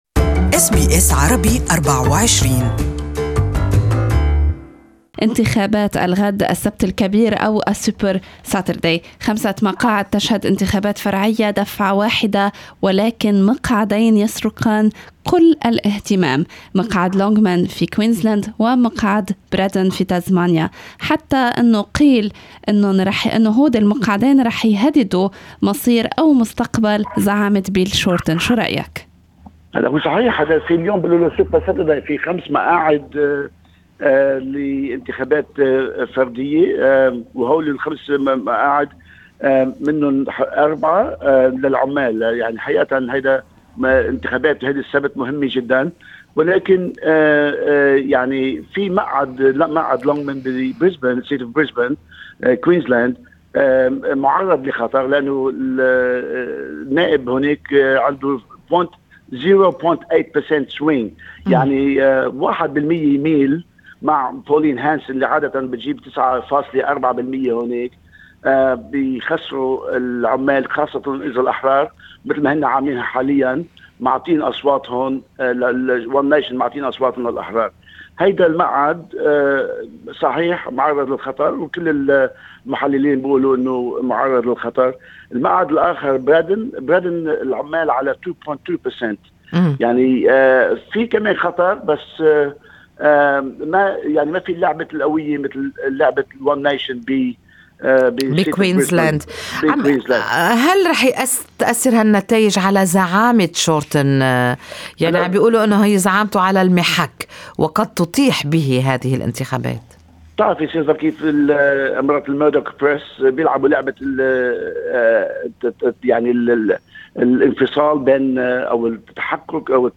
Good Morning Australia interviewed Opposition Whip in NSW parliament Shawkat Musulmani to comment about Super Saturday elections.